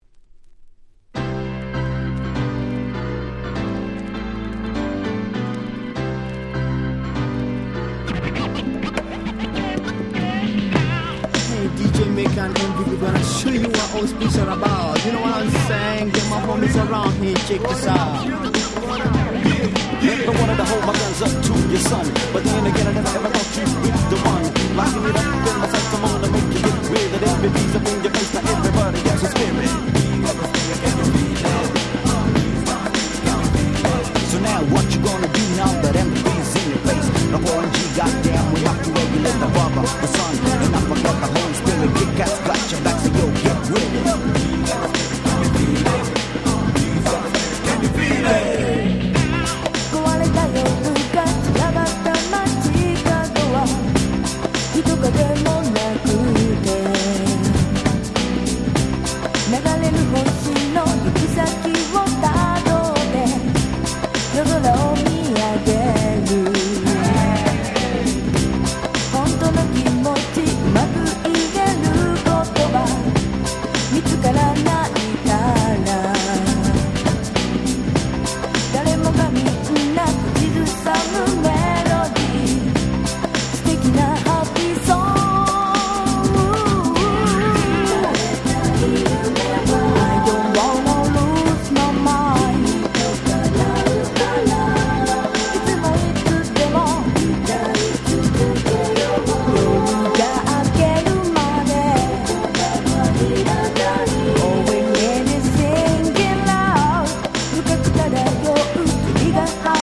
97' 謎のマイナー女性Vocal Japanese R&B !!
しかも内容がGround Beat風となれば、、、